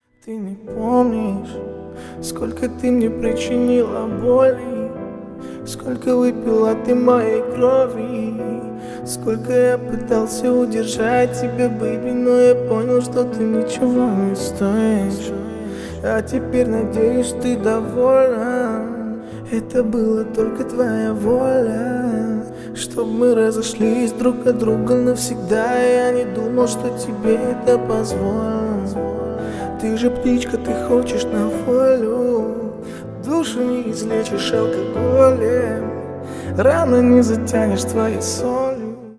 Поп Музыка
грустные # кавер # спокойные